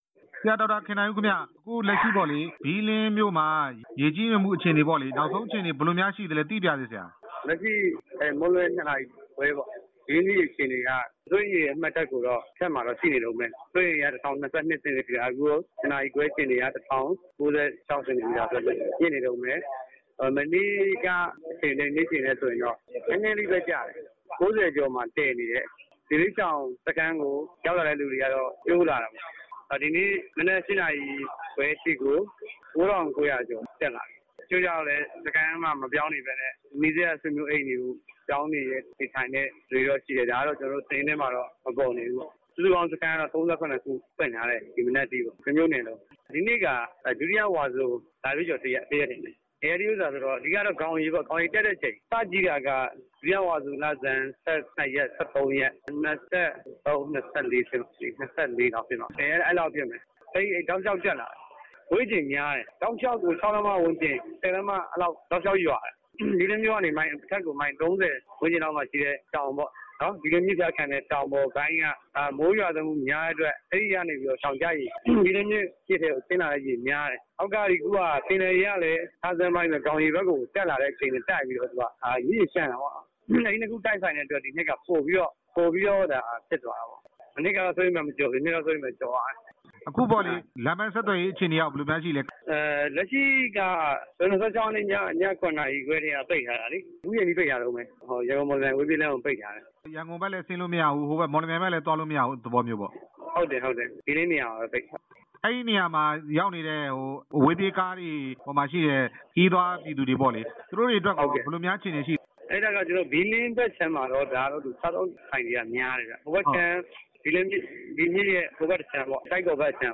ရေကြီးနေတဲ့ ဘီးလင်းမြို့ အခြေအနေ မေးမြန်းချက်
မေးမြန်းခန်း